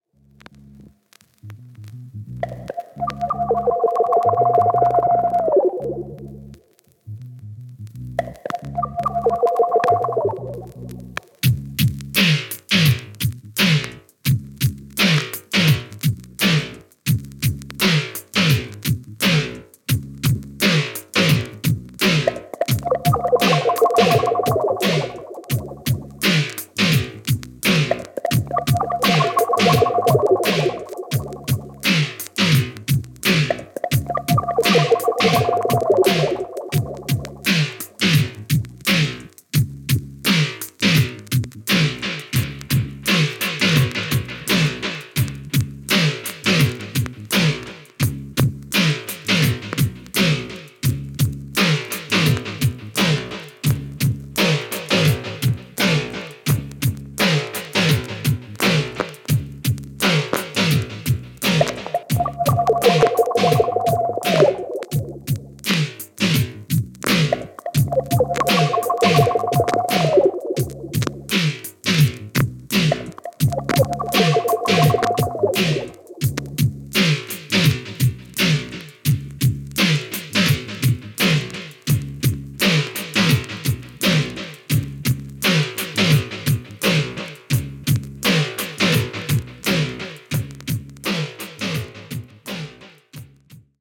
Side : B(キズあります)